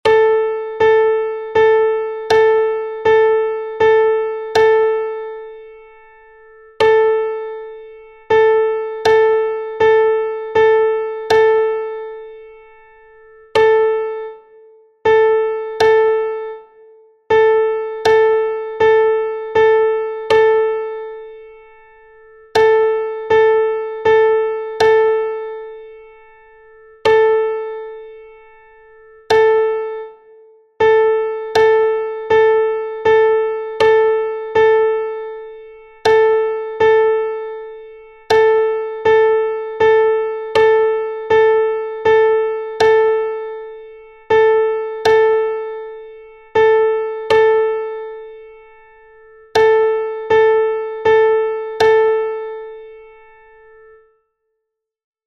Rhythmic dictation
dictado_ritmico_9_8_con_metronomorightspeed.mp3